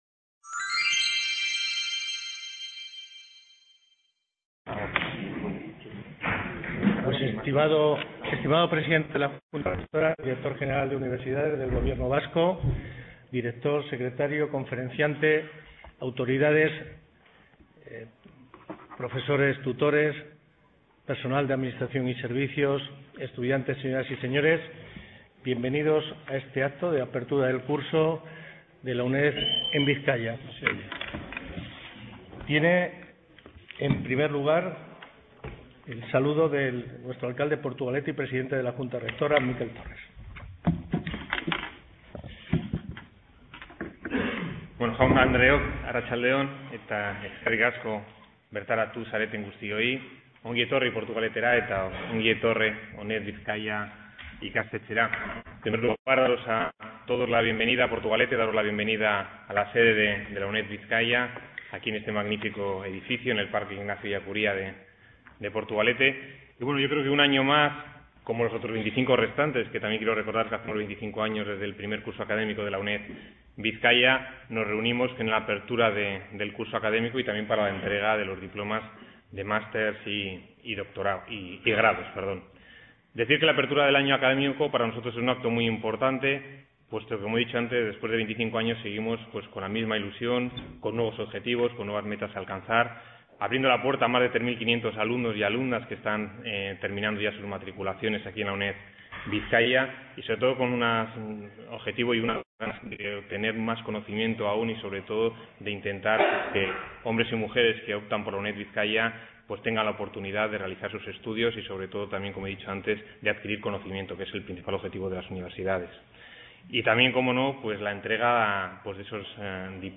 Acto Académico de Inauguración del Curso 2015-2016, se procederá también a la entrega del Premio Literario XXV Aniversario UNED Bizkaia y la entrega de diplomas a los titulados del curso 2014-2015